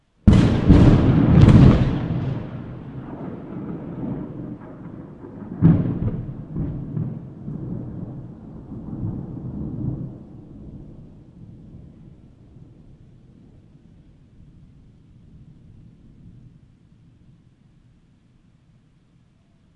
风暴雷雨氛围背景声
描述：风暴雷雨氛围背景声。
Tag: 放松 背景声 地下 空间 洞穴 闪电 声景 风暴 实地录音 老旧 外星人 微妙 一般噪音 自然 深度 天气 下雨 环境 回声 环境音 气氛